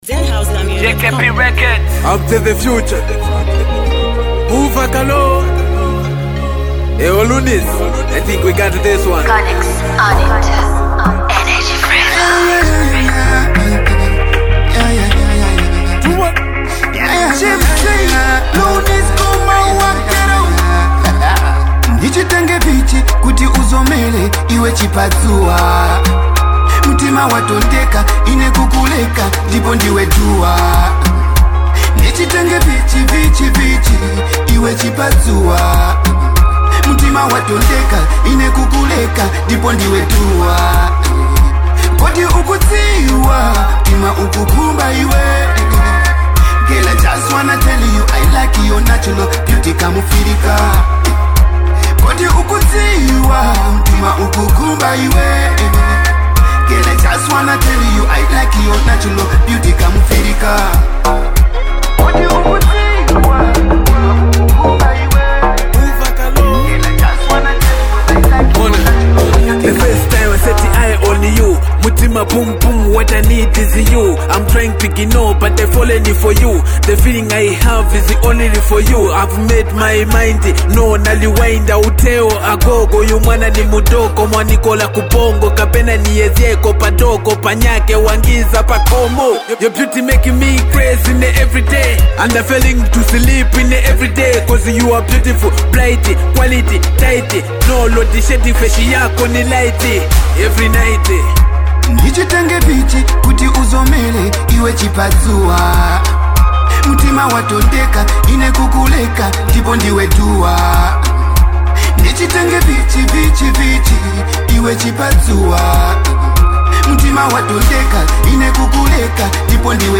heartfelt melodies